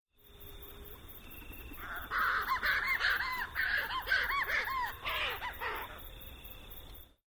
ʻAʻo (Newell’s Shearwater) Call
ao-newells-shearwater-call.wav